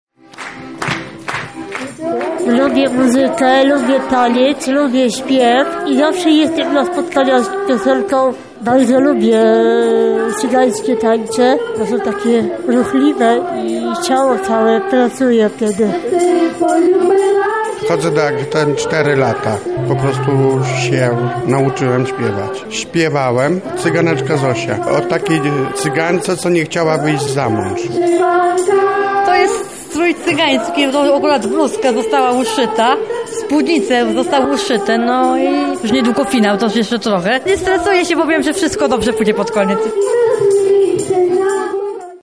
Uczestnicy opowiedzieli jak bawili się przy takich rytmach.